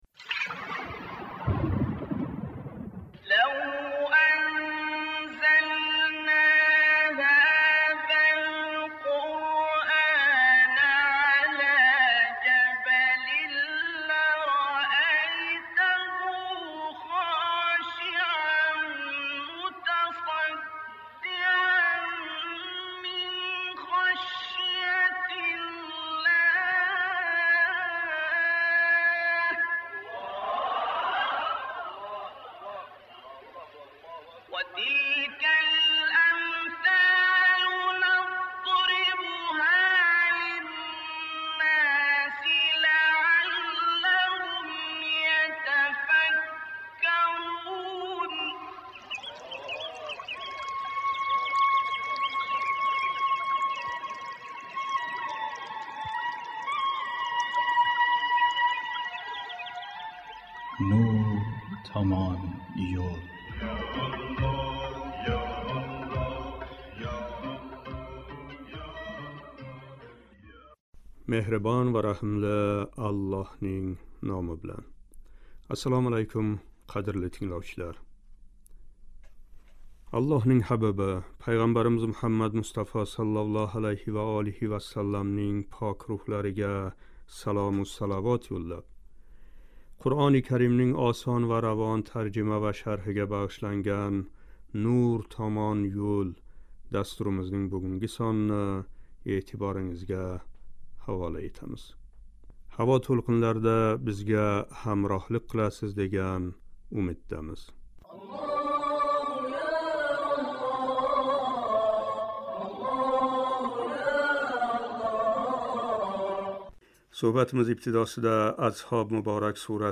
750-қисм« Аҳзоб " муборак сураси 18-21-ояти карималарининг шарҳи. Суҳбатимиз ибтидосида «Азҳоб " муборак сураси 18-19-ояти карималарининг тиловатига қулоқ тутамиз: